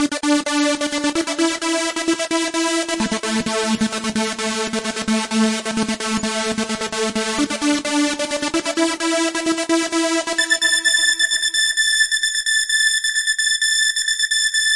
描述：合成短语舞蹈循环。由FL工作室软件制作，Harmor合成器。
Tag: 电子 短语 狂野 合成器 最小 音乐 高科技 循环 精神恍惚 房子 danc E 空间 spacesynth 俱乐部